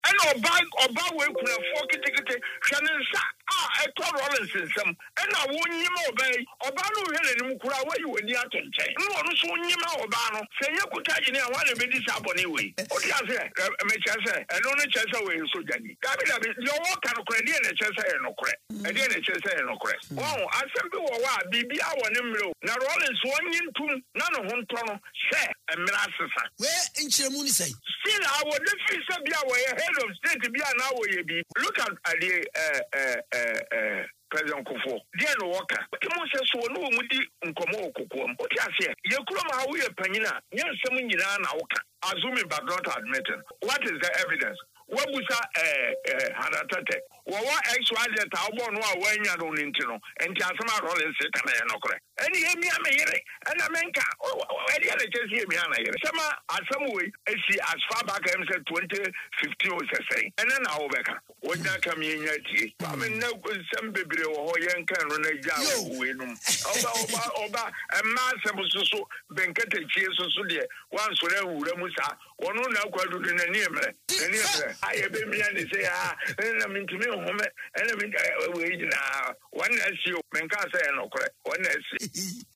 “Naadu never complained and so why should you come out and complain about a mere handshake? He is just not being fair to Hannah Tetteh and it’s wrong”, he added on Accra-based radio station Okay FM.